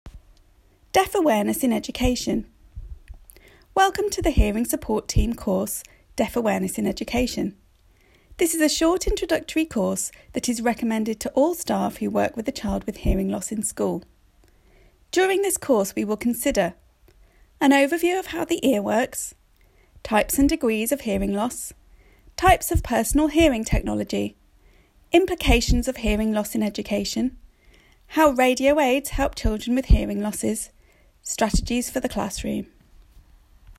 If you prefer to hear the audio description on each page, press the play button, highlighted here:  This is located at the left hand side on each audio bar.